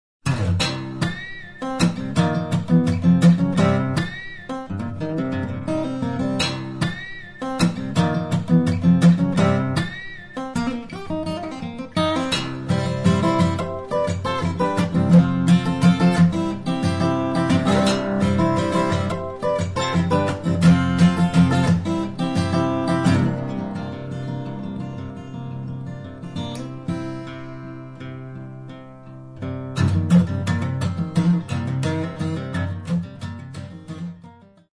fingerstyle guitar solos on 6 and 12 string guitars
solos on 6 and 12 strings, as well as ukulele